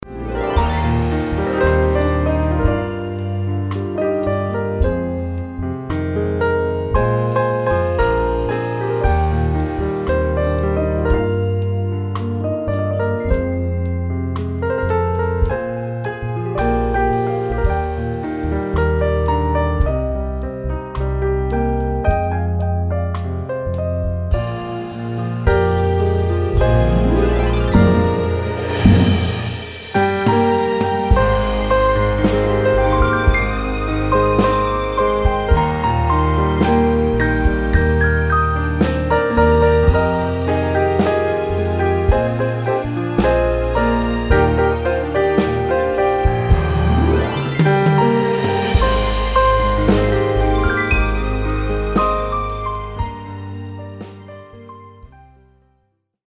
Pianist
instrumental recordings